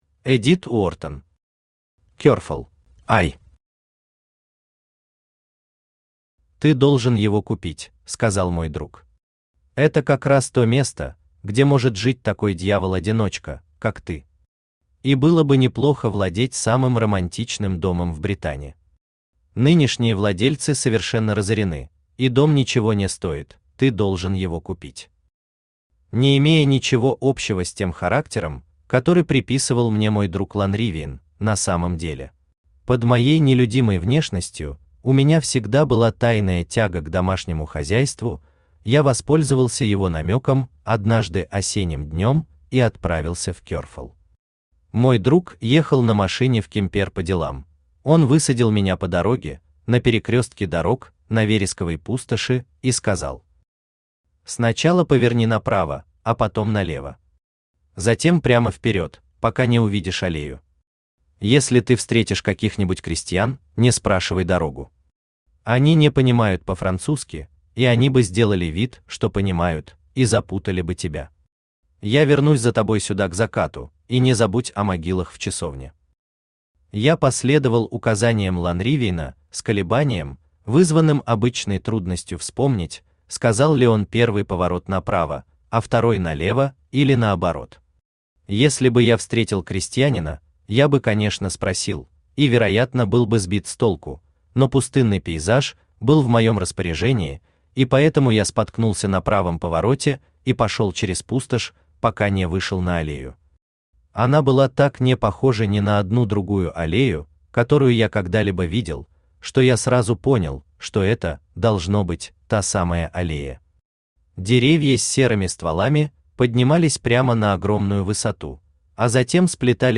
Aудиокнига Керфол Автор Эдит Уортон Читает аудиокнигу Авточтец ЛитРес.